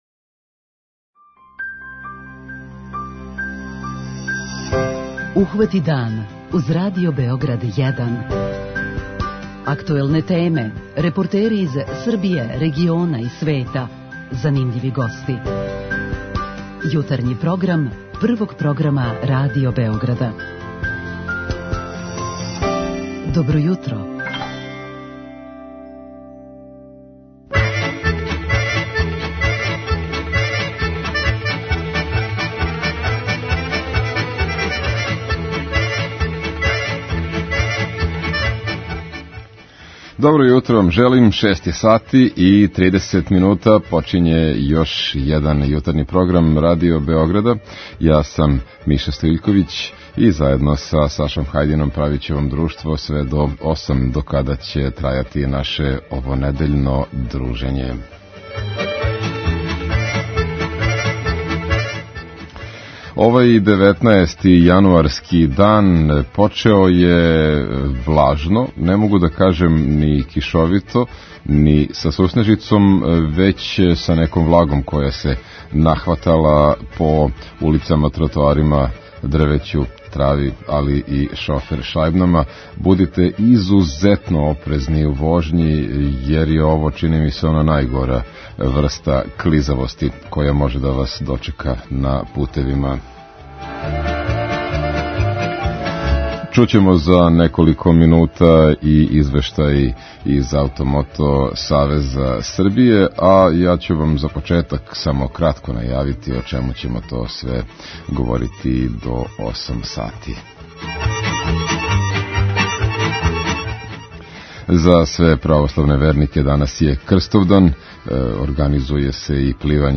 У Србији је, наиме, у току попис птица водених станишта. Тим поводом емитоваћемо разговор с волонтерима који га спроводе.